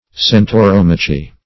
Search Result for " centauromachy" : The Collaborative International Dictionary of English v.0.48: Centauromachy \Cen`tau*rom"a*chy\, n. [Gr.